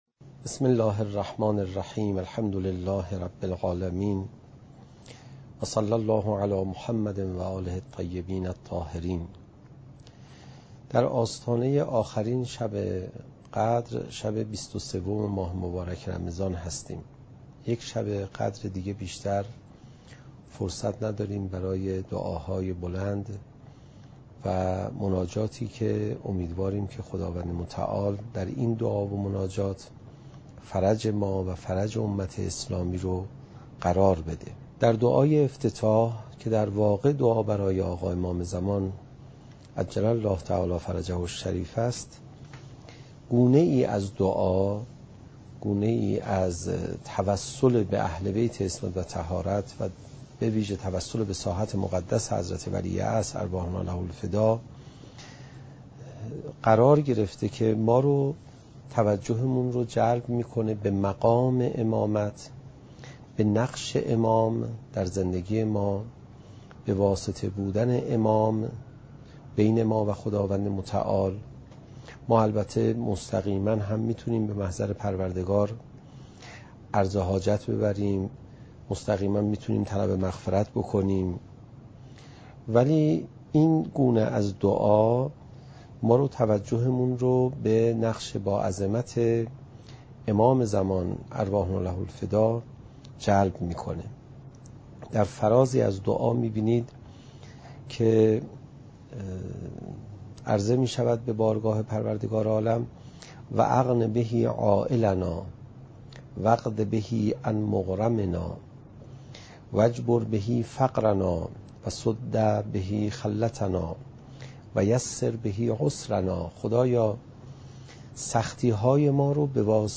قاری : حجت الاسلام عليرضا پناهیان